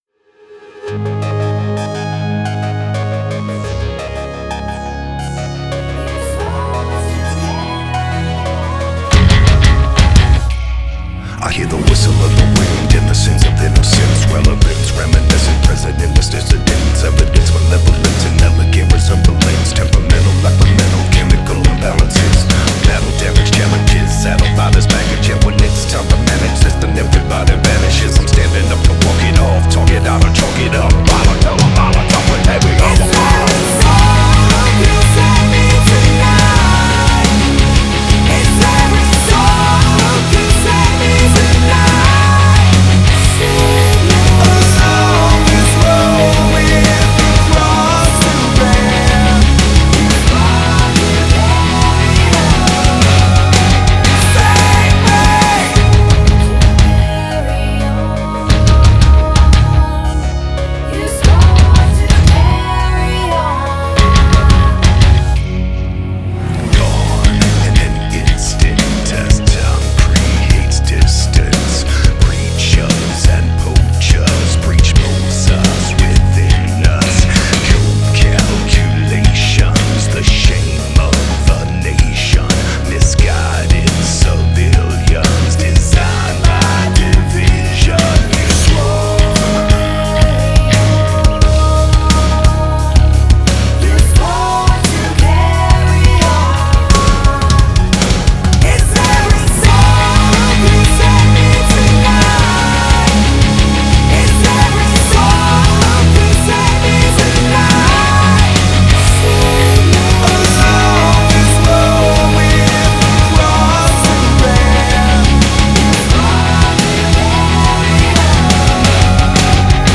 Жанр: Metal, Rock